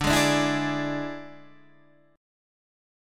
D Suspended 2nd Flat 5th